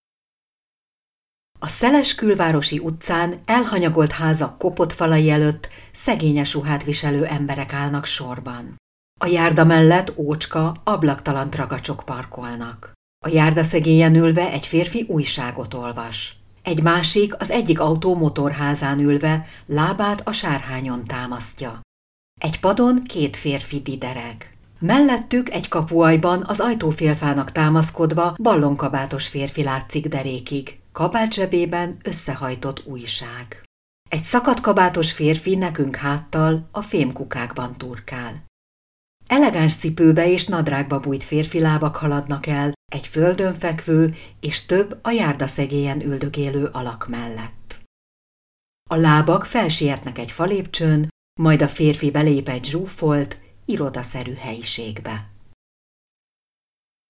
AUDIONARRÁTOROK TÁRSASÁGA
Hangminta-_-reszlet-A-nagy-balhe-c.-film-audionarracios-szovegkonyvebol.mp3